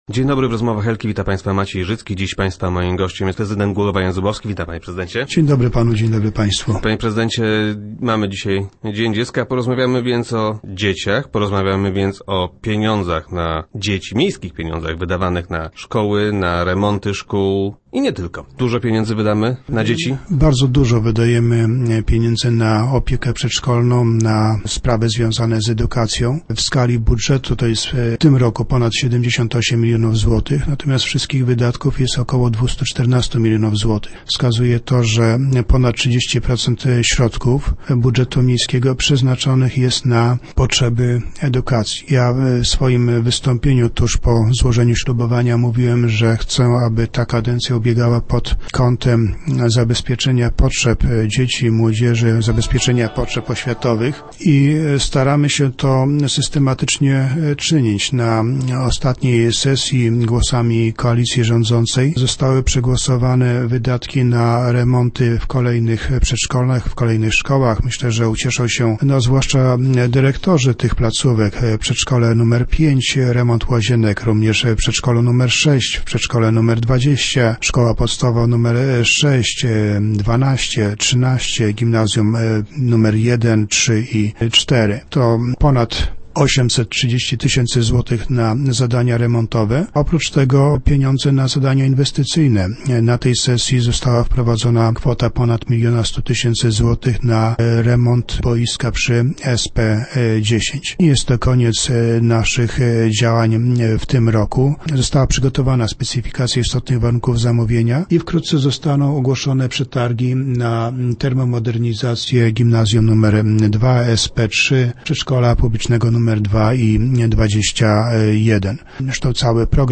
- Bardzo dużo wydajemy na opiekę przedszkolną i na sprawy związane z edukacją - twierdzi prezydent Jan Zubowski, który był gościem Rozmów Elki.